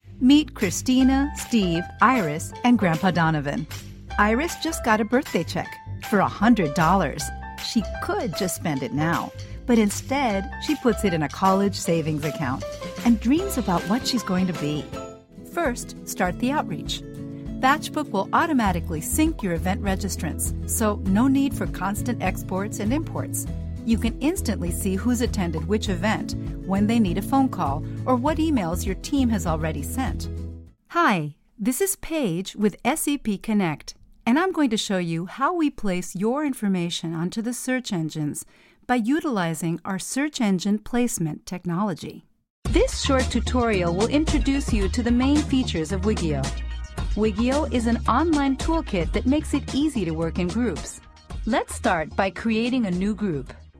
Erklärvideos
Meine Kunden beschreiben meine Stimme am besten als freundlich, spritzig, ausdrucksstark, angenehm, warm und enthusiastisch